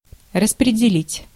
Ääntäminen
IPA: /dis.tʁi.bɥe/